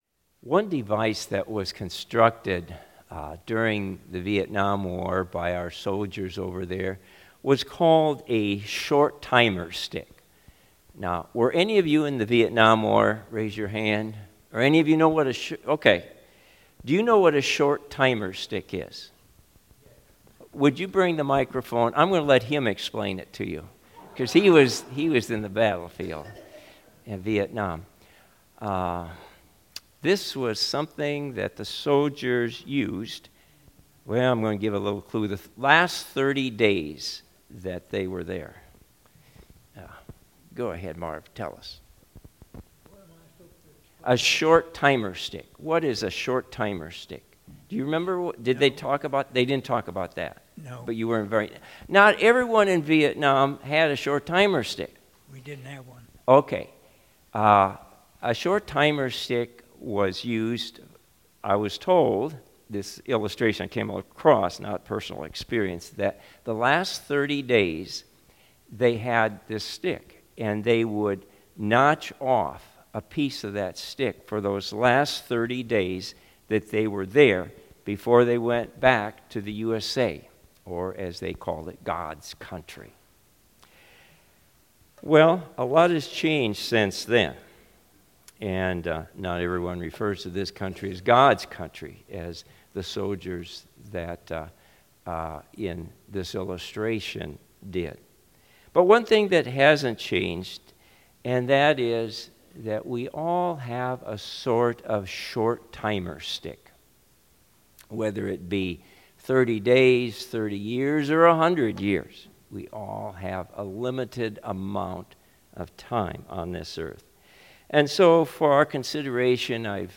Sermon-2025-08-03.mp3